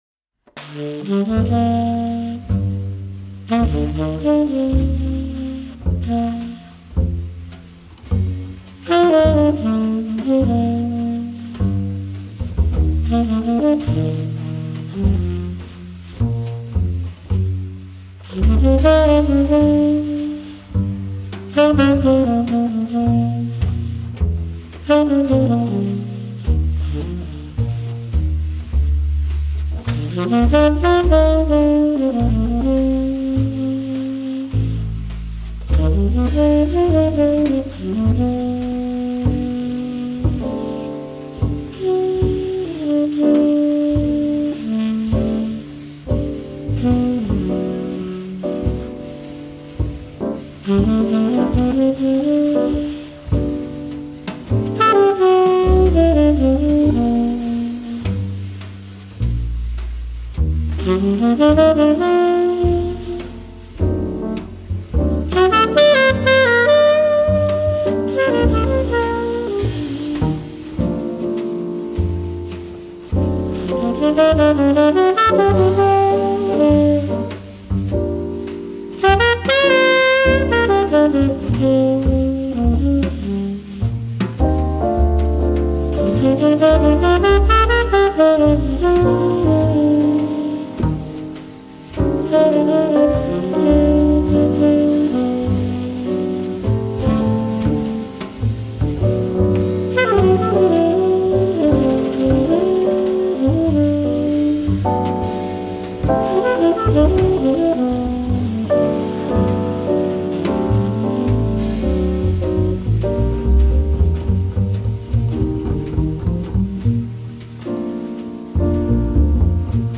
tenorsaxophone
piano
bass
drums.